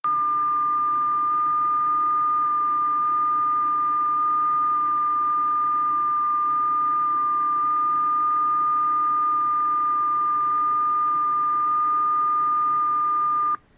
JT9 modalità ottimizzata per deboli segnali sulle bande LF, MF e HF. JT9 è di circa 2 dB più sensibili di JT65 e utilizza meno del 10% della larghezza di banda.